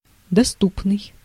Ääntäminen
US : IPA : [ˈpɑp.jə.lɚ]